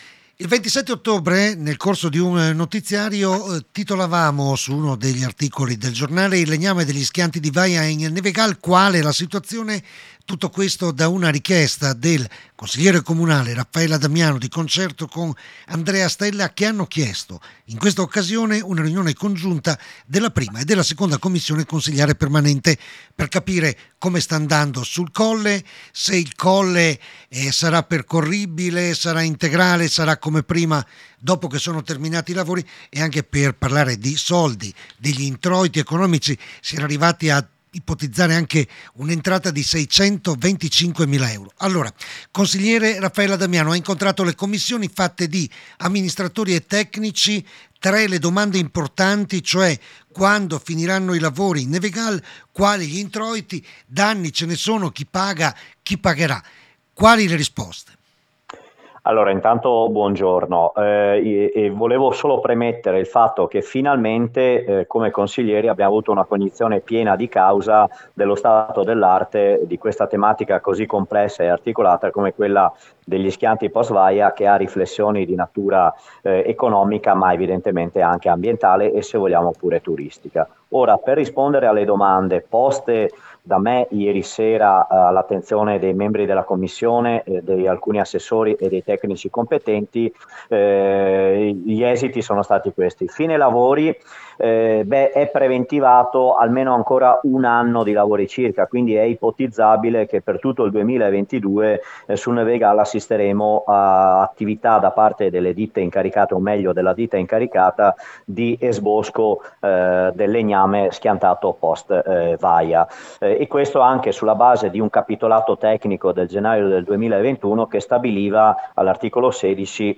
Ospite del giornale delle 16 il consigliere comunale Raffaele Addamiano dopo la riunione fiume di ieri sera con amministratori e tecnici. Lo stato dell’arte di una vicenda difficile dopo la tempesta Vaia.